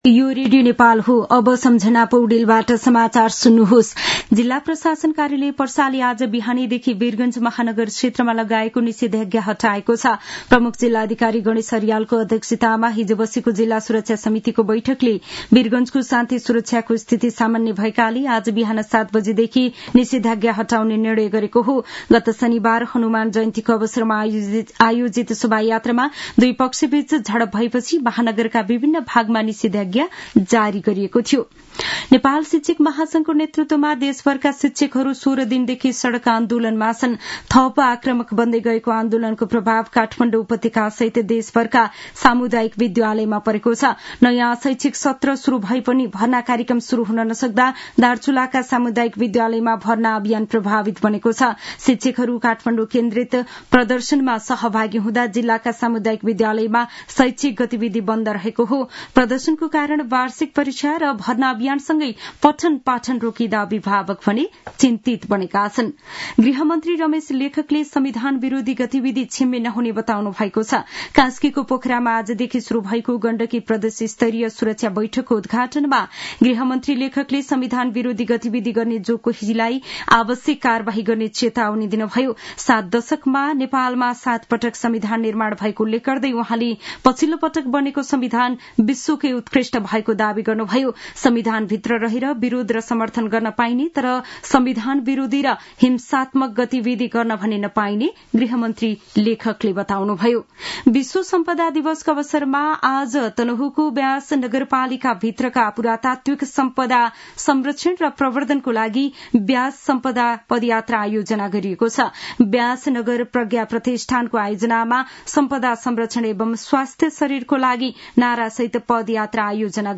दिउँसो १ बजेको नेपाली समाचार : ५ वैशाख , २०८२